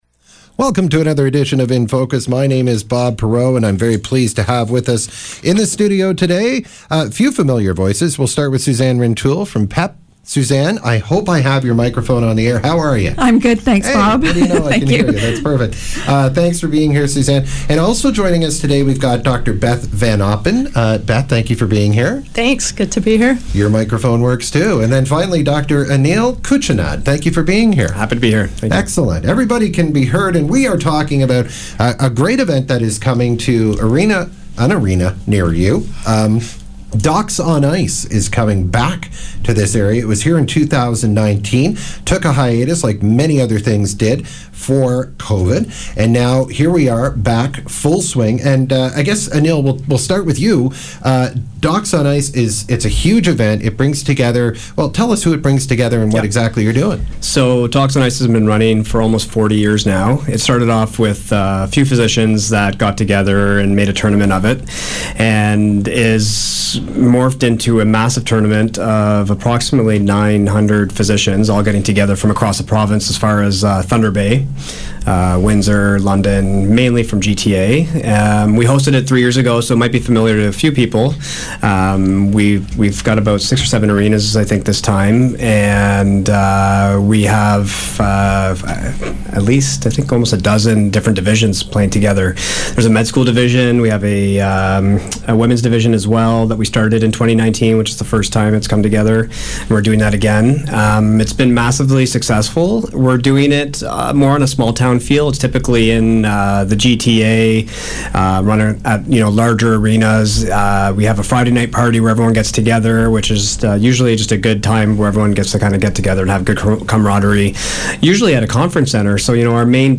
to the studio to talk about the upcoming tournament DOCS on Ice taking place across the region at the end of the month.